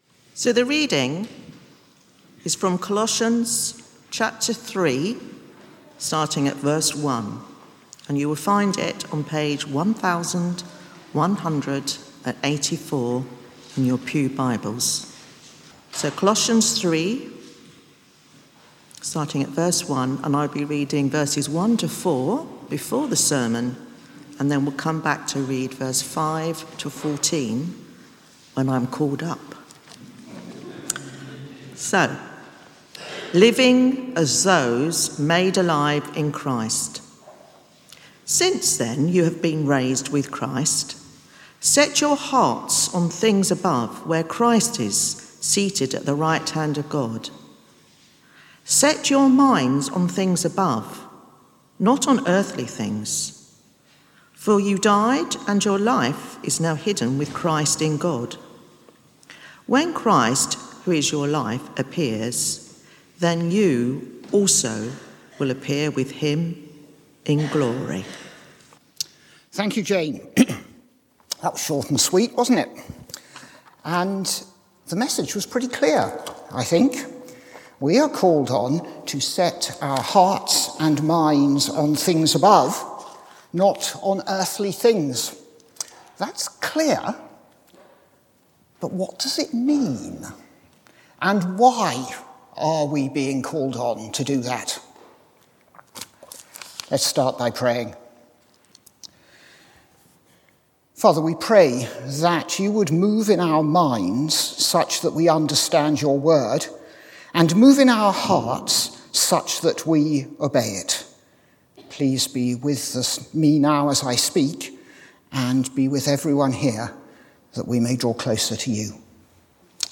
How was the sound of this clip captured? From Service: "10.15am Service"